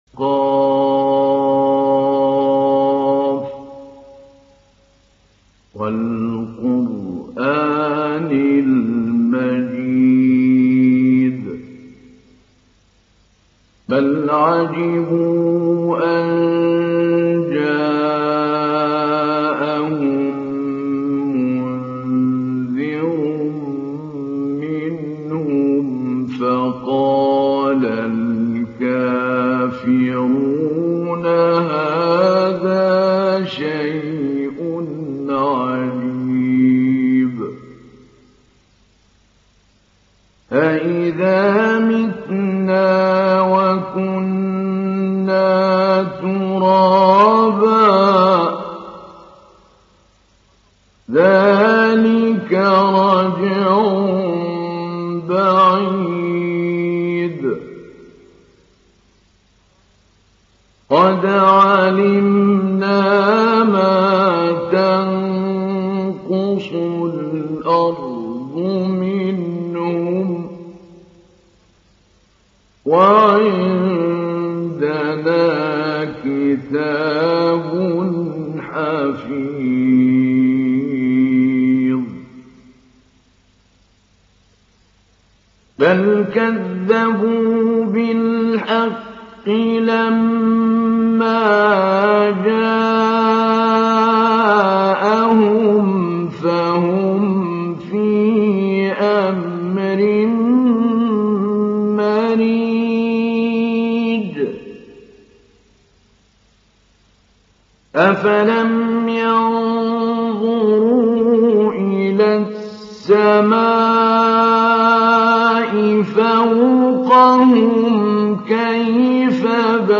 تحميل سورة ق محمود علي البنا مجود